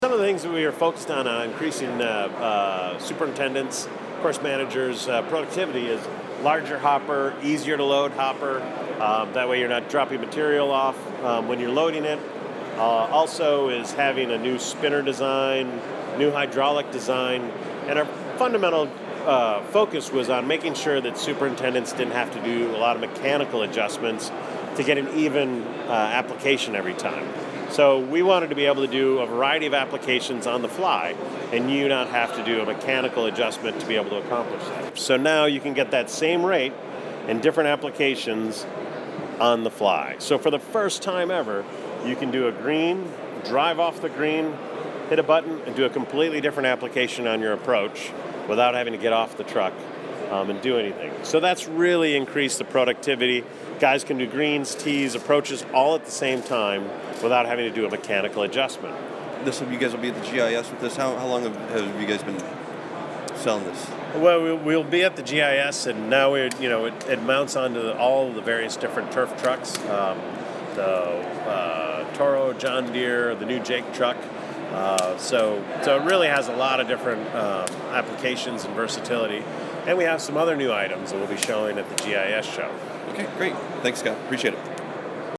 It was my second trip to the BIGGA Turf Management Exhibition in Harrogate, England, so while I wasn’t as wide-eyed as I was my first time attending the show, I still was impressed to see equipment that hasn’t yet been out in the open in the U.S.
Along with the interviews below, we also took video of each item for an exclusive Golfdom TV video tour.